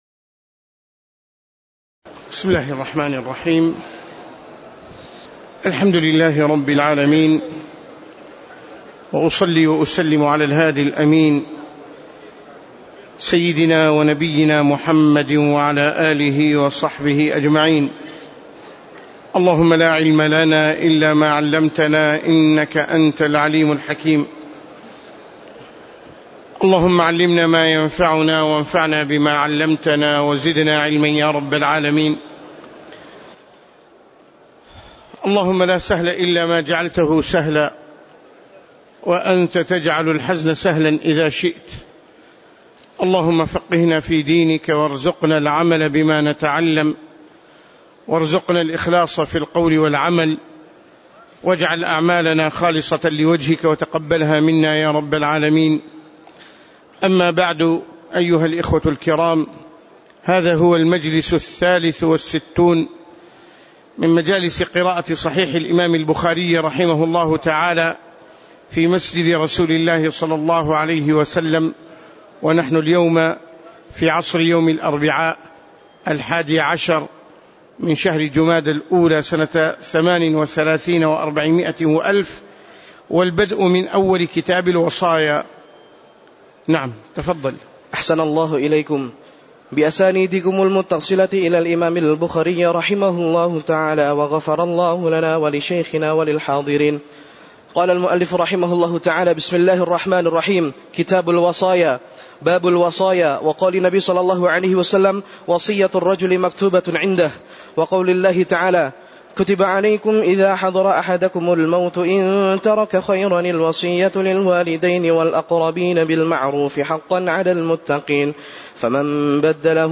تاريخ النشر ١٠ جمادى الأولى ١٤٣٨ هـ المكان: المسجد النبوي الشيخ